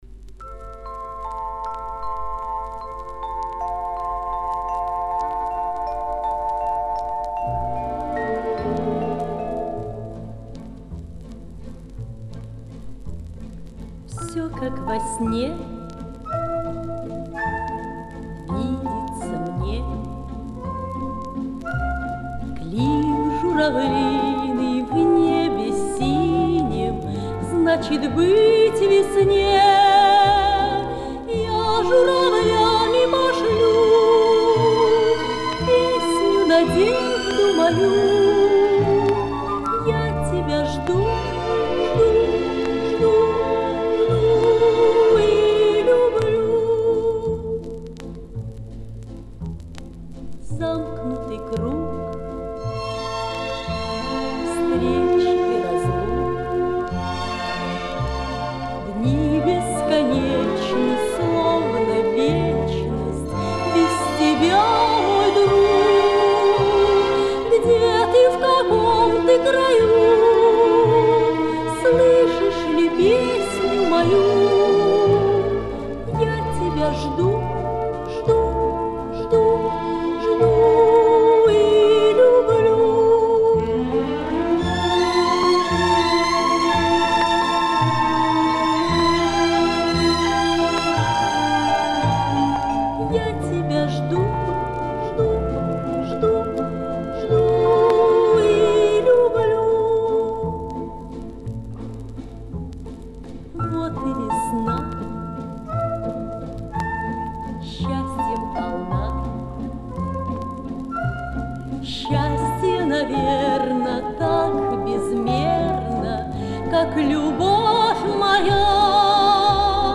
Лирическая песня.